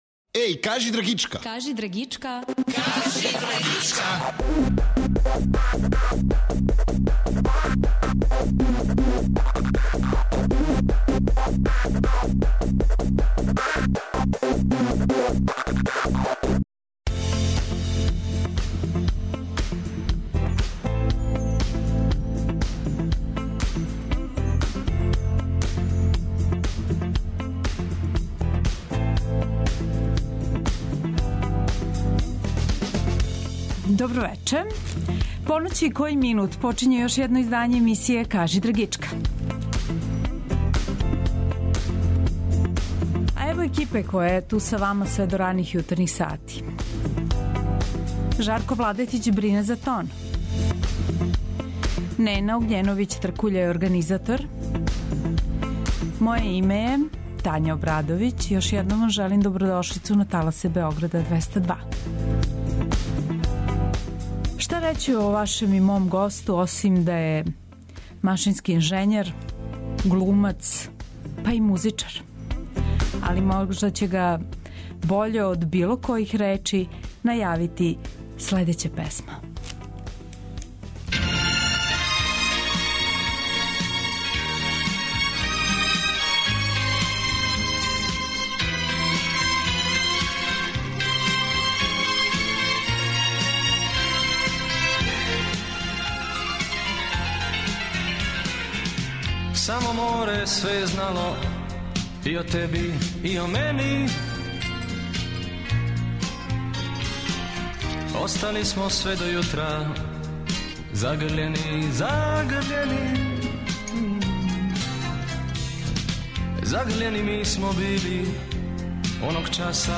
Гост емисије је Франо Ласић, хрватски глумац и музичар.